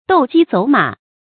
斗雞走馬 注音： ㄉㄡˋ ㄐㄧ ㄗㄡˇ ㄇㄚˇ 讀音讀法： 意思解釋： 斗雞賽馬。古代的賭博游戲。